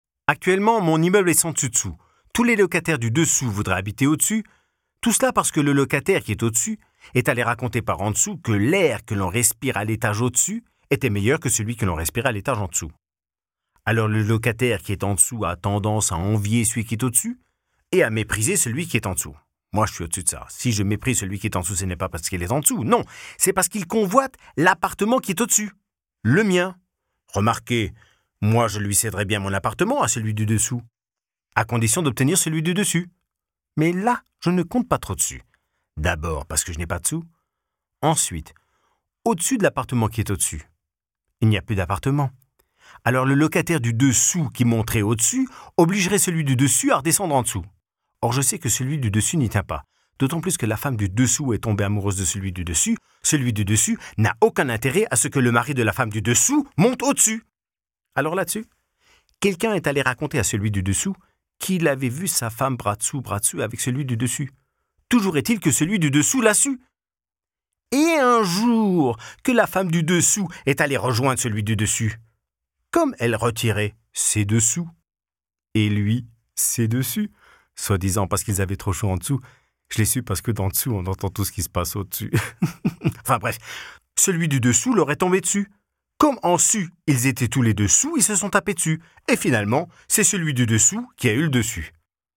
Französischer Schauspieler und Sprecher, Muttersprache französisch, deutsch mit französischem Akzent
Sprechprobe: Industrie (Muttersprache):
voice over artist french